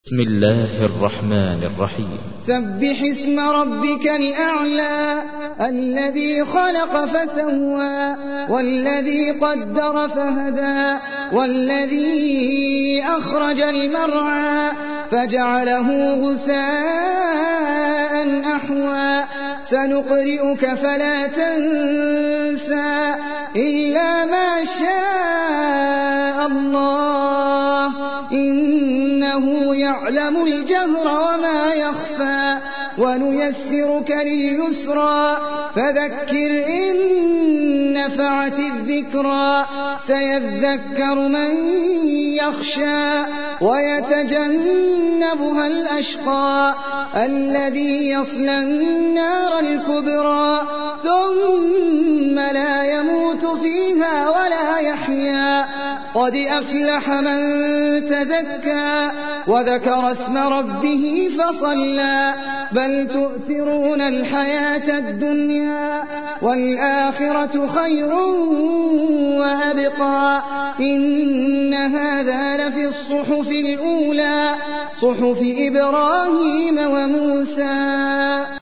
تحميل : 87. سورة الأعلى / القارئ احمد العجمي / القرآن الكريم / موقع يا حسين